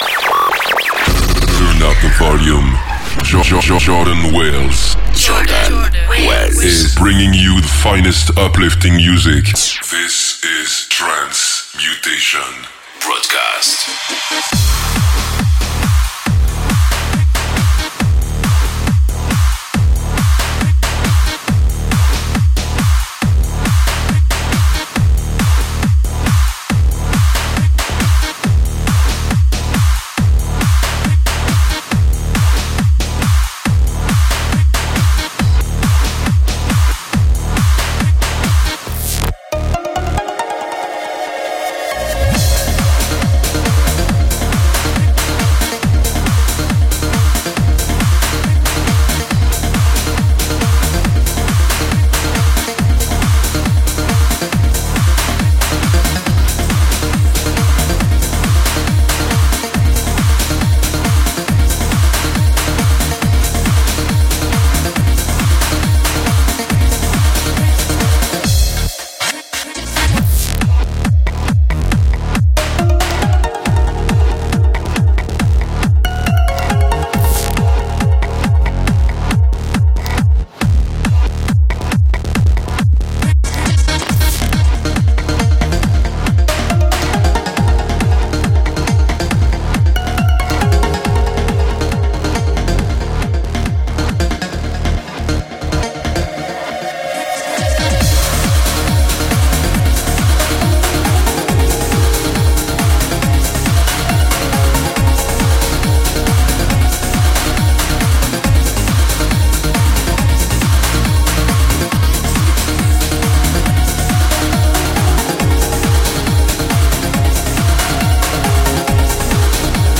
uplifting